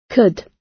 Transcription and pronunciation of the word "could" in British and American variants.
could__gb_1.mp3